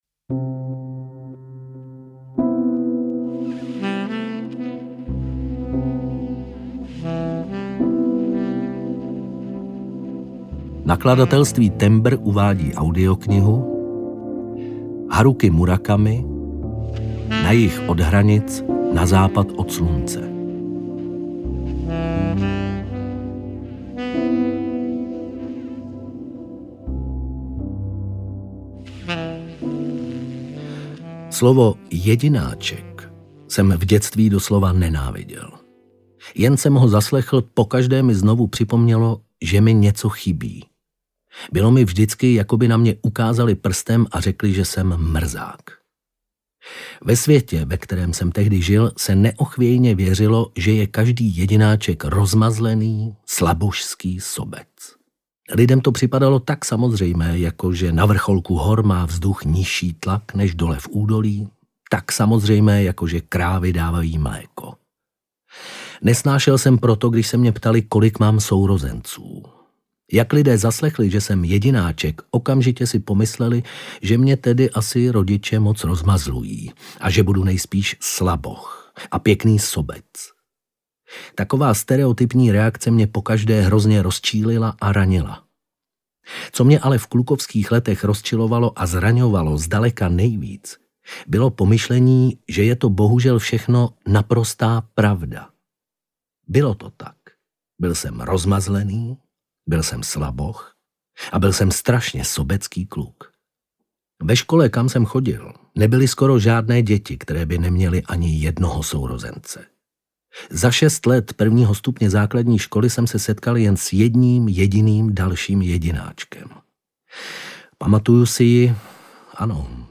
Na jih od hranic, na západ od slunce audiokniha
Ukázka z knihy